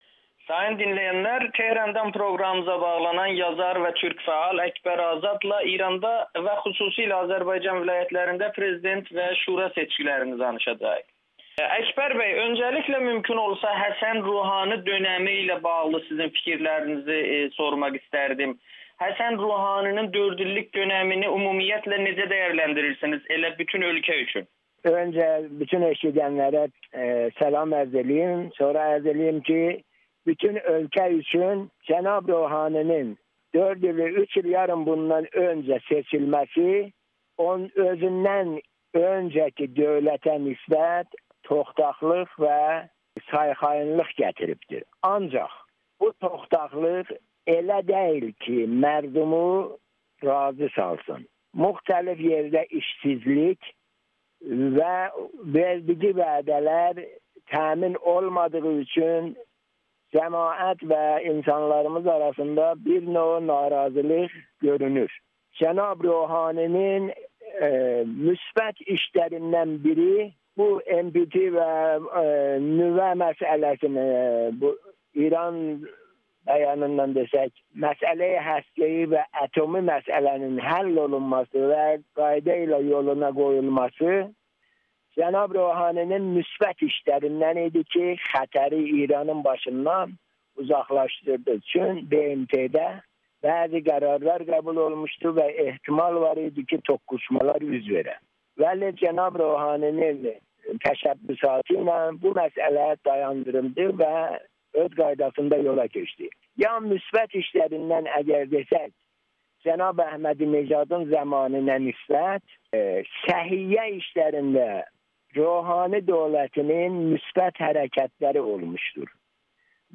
Prezident Ruhaninin türklərə verdiyi vədlərin əksinə əməl edildi [Audio-Müsahibə]
Tanınmış yazar Tehrandan Amerikanın Səsinə danışıb